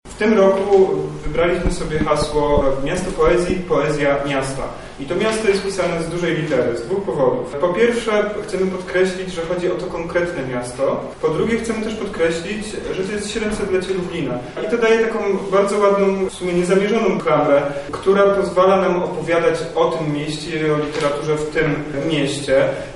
Miasto Poezji konfa
Miasto-Poezji-konfa.mp3